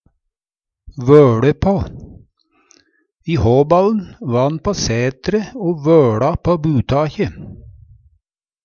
Høyr på uttala Ordklasse: Uttrykk Kategori: Reiskap og arbeidsutstyr Handverk (metall, tre, lær) Attende til søk